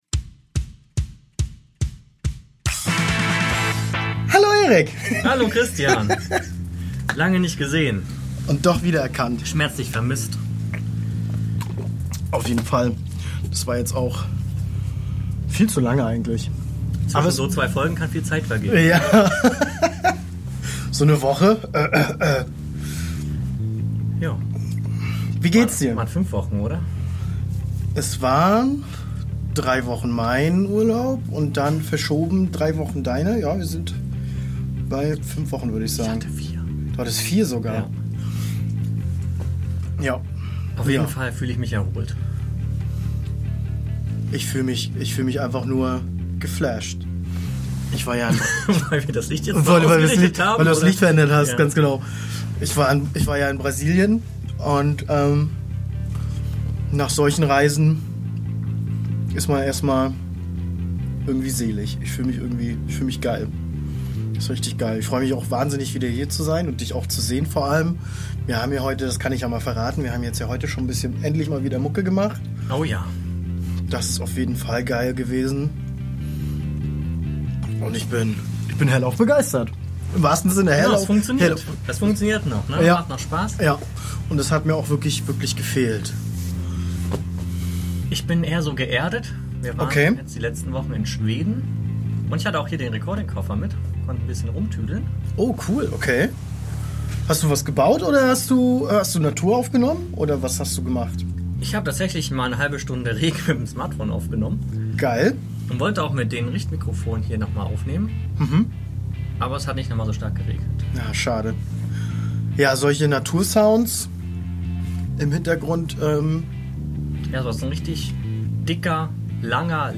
Nach 4 Wochen Urlaubstrennung finden wir uns im Proberaum zusammen und besprechen Eindrücke und Ideen, die wir gesammelt haben. Darunter fällt der WUX, unser imaginäres drittes Bandmitglied, das vor allem durch sein aktives Nichtstun in den Vordergrund rückt.